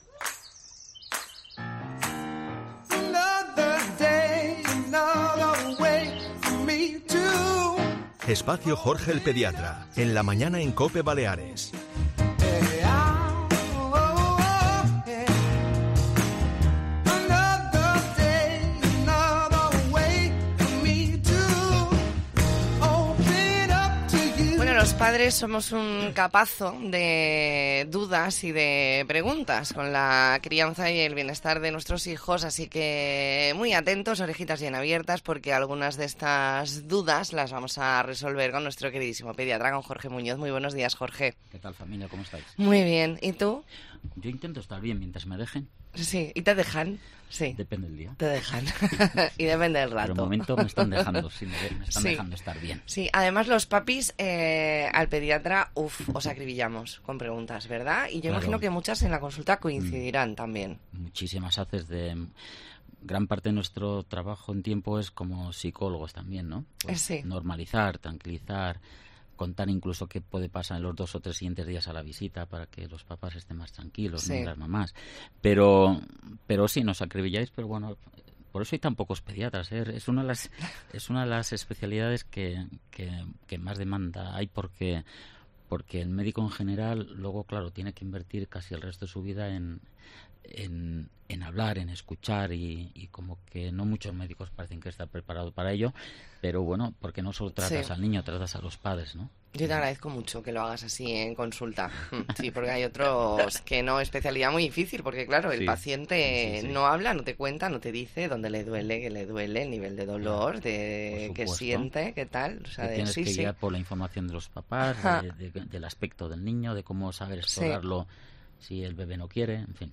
Hoy resuelve algunas de las principales dudas de los padres. Entrevista en La Mañana en Baleares, martes 17 de octubre de 2023.